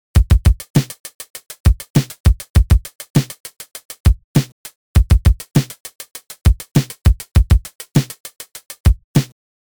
На бесплатных сэмплах попробовал повторить. Только не через LFO конечно старт хэта рандомить надо, ну да ладно.